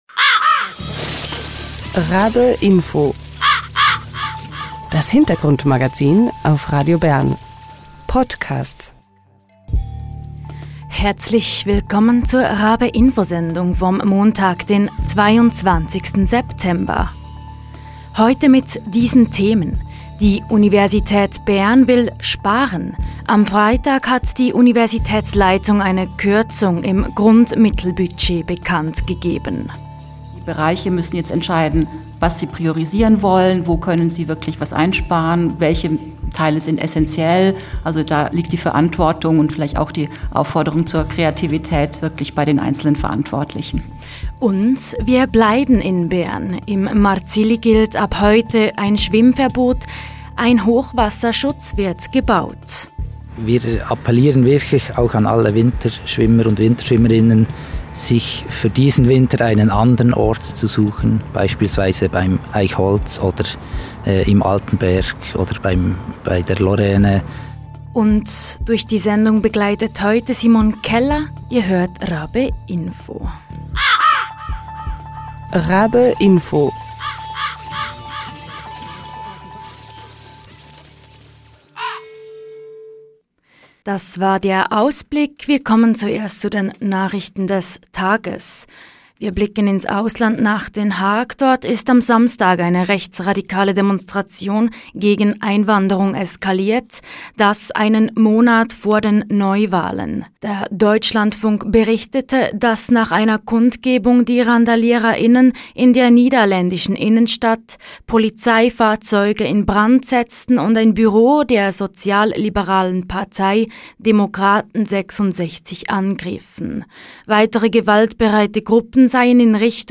Rektorin Virginia Richter gibt Auskunft über die Pläne der Universitätsleitung.